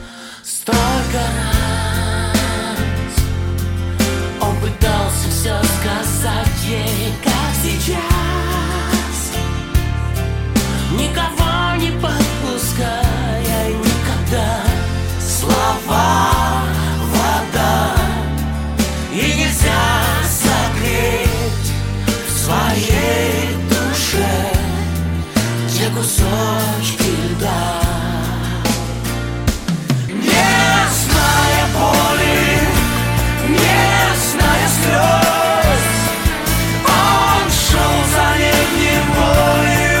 поп , дуэт